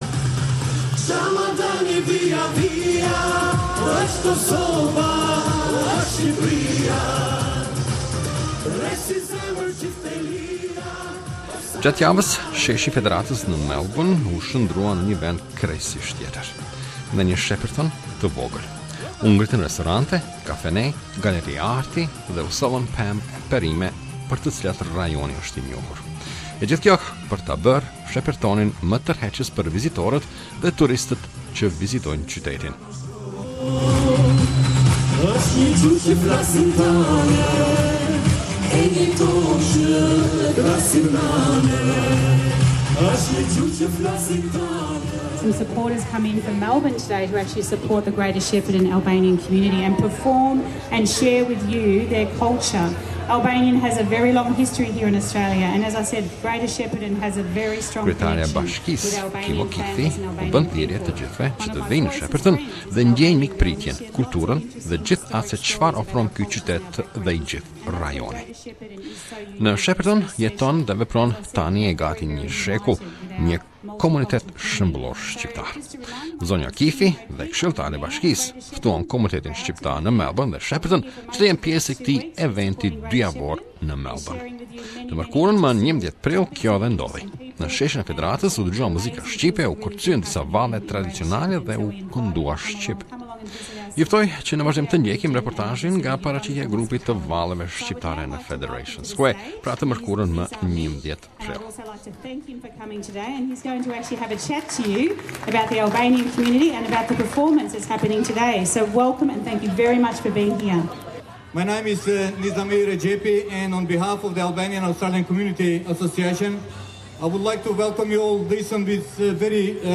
(9 to 21 April at the Federation Square) This week Federation Square in Melbourne was home to mobile restaurants, a coffee shops, an art gallery fruits and vegetables and everything come from Shepparton. All this to make the city more attractive for visitors and tourists
Mayor of the Municipality Kim O'Keefe calls on everyone to come and feel the culture and all that this city and the whole region can offer.